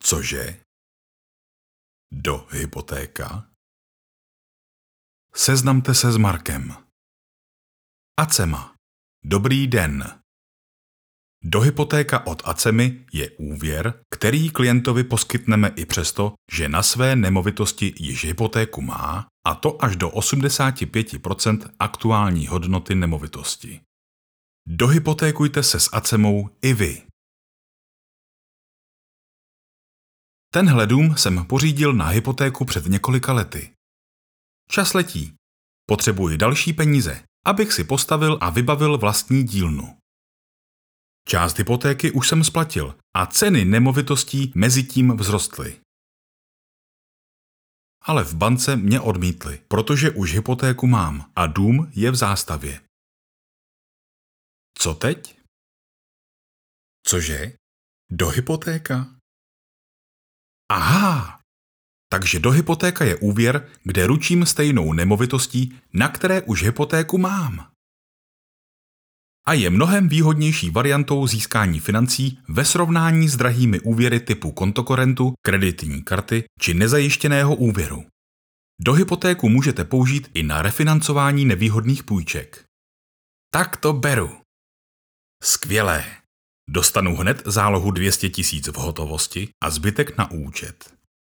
Součástí každého jobu je i základní postprodukce, tedy odstranění nádechů, filtrování nežádoucích frekvencí a ekvalizace a nastavení exportu minimálně 96kHz/24bit, okolo -6dB, jestli se nedohodneme jinak.
Mužský voiceover - hlas do krátkých reklamních spotů!